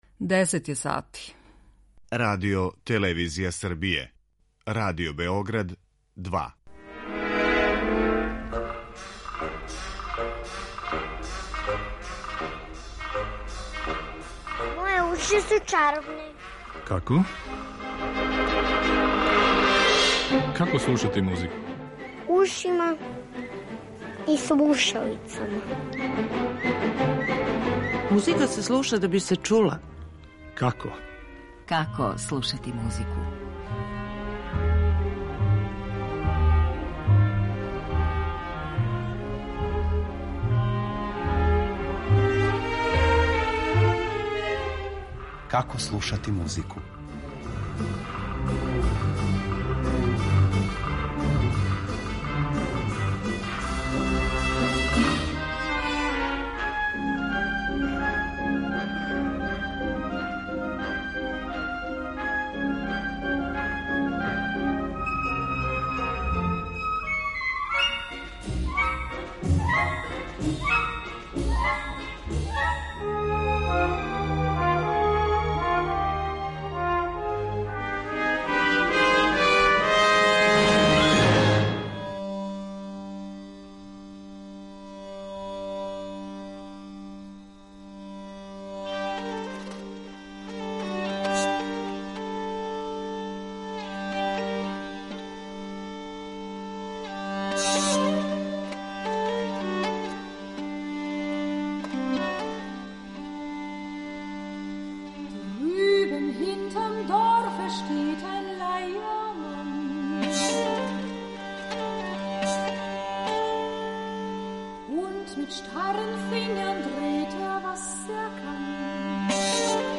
Међу музичким примерима биће и необично извођење уз пратњу фолклорног инструмента познатог као харди-гарди.